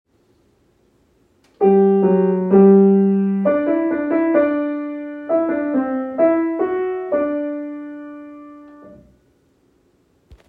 In Example 10.5.10, the top part has the basic melody. At the same time, the bottom part sings the melody with alterations or embellishments. When this occurs, the music has a heterophonic texture.